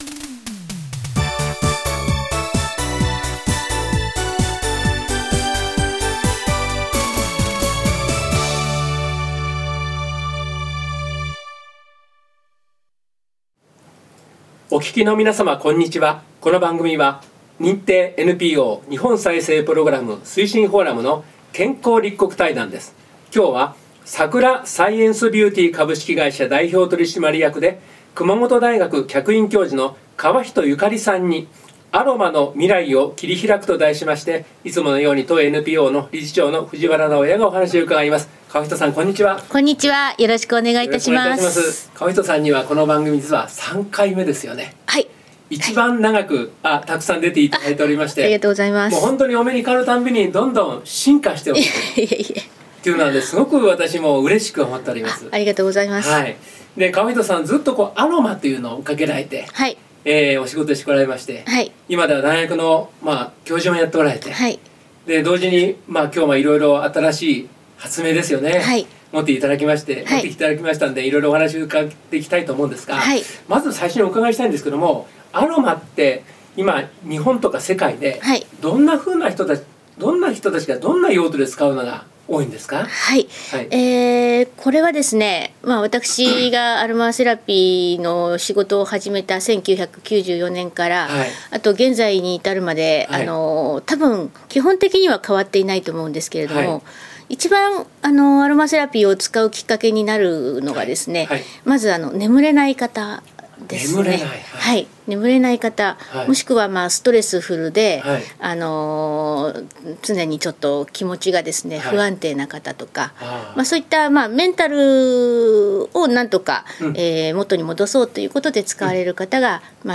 健康立国対談